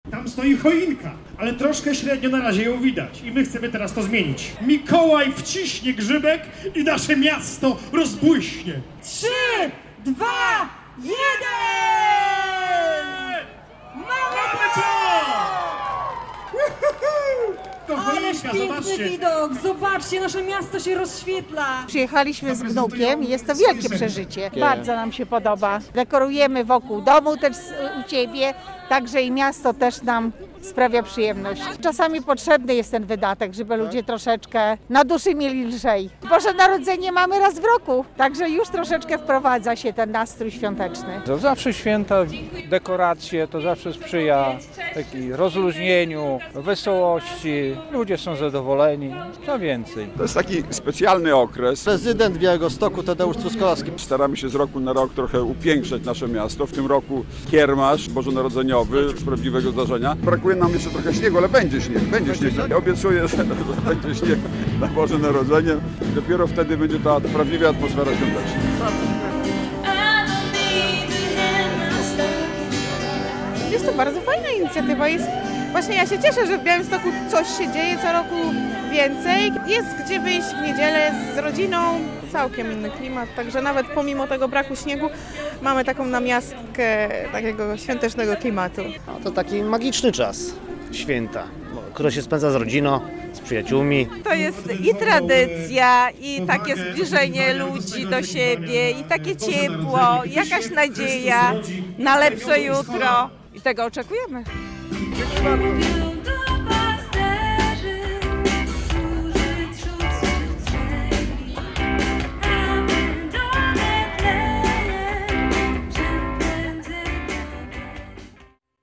Mikołaj z Rovaniemi i prezydent miasta Tadeusz Truskolaski w niedzielę (8.12) oficjalnie uruchomili świąteczne iluminacje w Białymstoku. W dorocznym wydarzeniu wzięło udział wielu mieszkańców miasta, którzy mimo padającej mżawki przyszli na Rynek Kościuszki, by podziwiać świąteczne dekoracje.
Zapalenie lampek na miejskiej choince - relacja